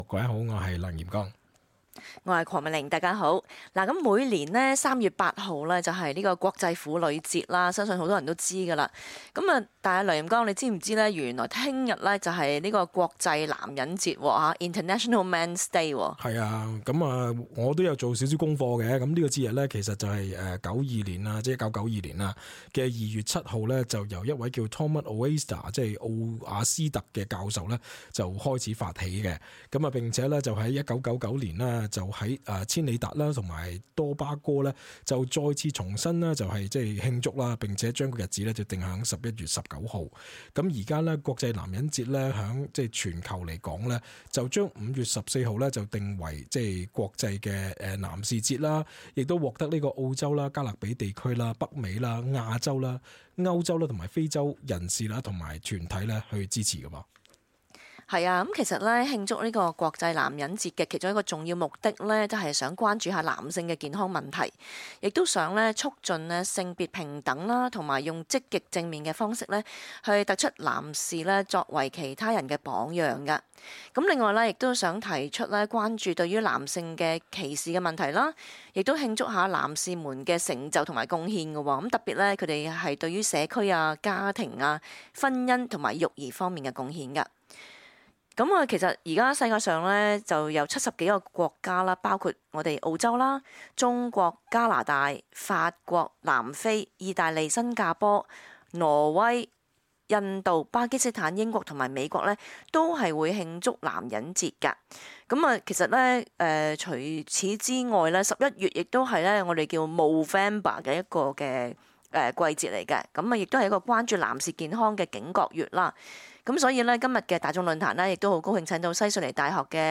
cantonese_-_talkback_-_nov_18_-_upload.mp3